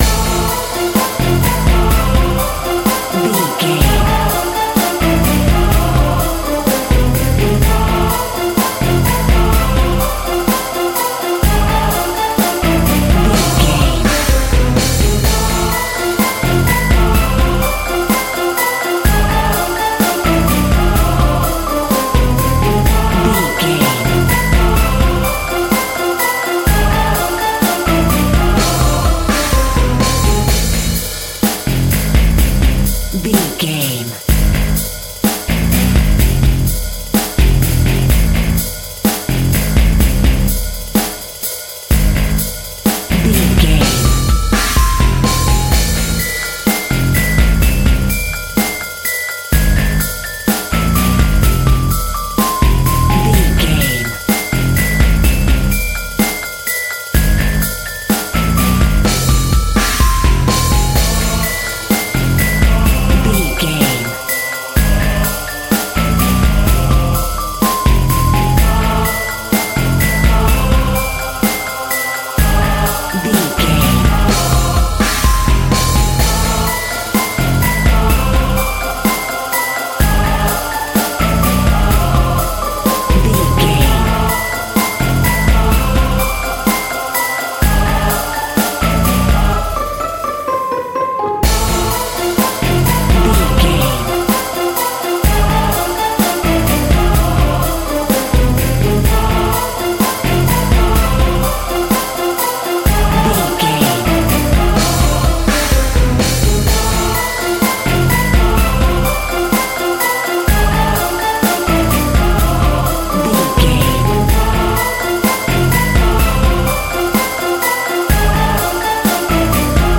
Aeolian/Minor
Fast
scary
ominous
dark
haunting
eerie
bass guitar
drums
synthesiser
strings
ambience
pads